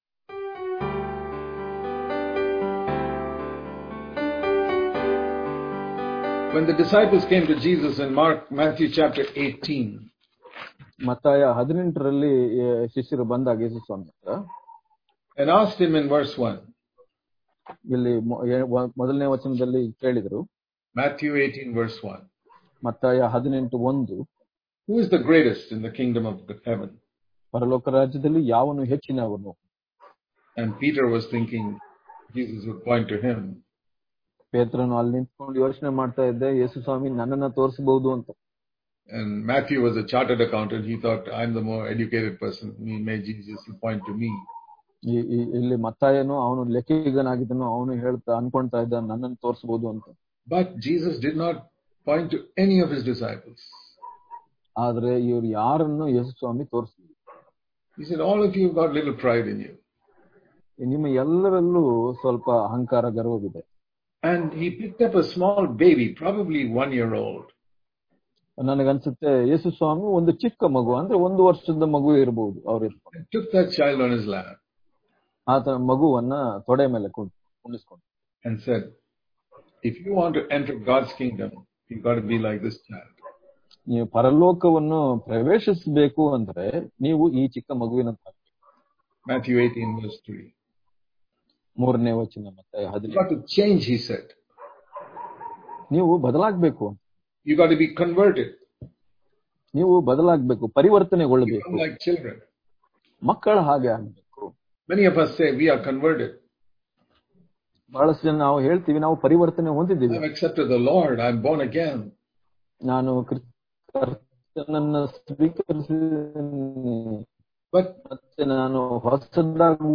May 12 | Kannada Daily Devotion | Desire With All Your Heart To Be Like Little Child Daily Devotions